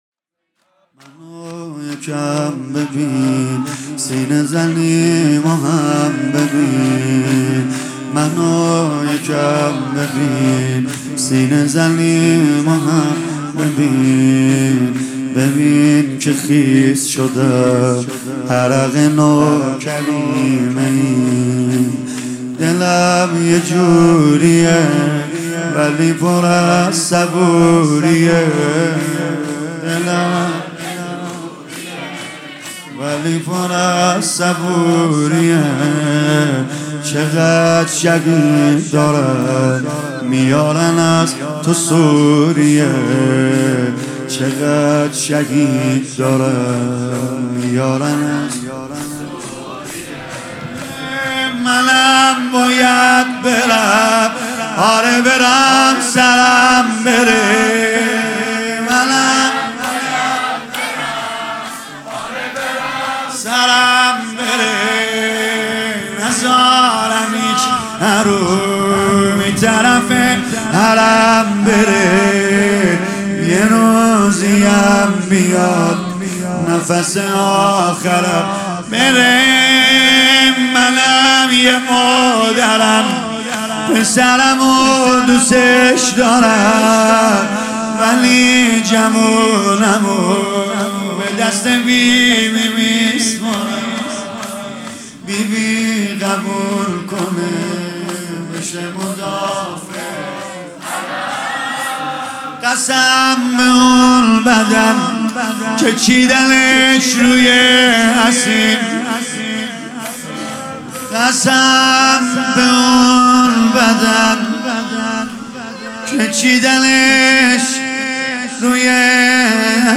شور | منو یکم ببین، سینه زنیمو هم ببین
شب دهم محرم ۹۹ - هیئت فدائیان حسین